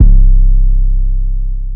808 (southside).wav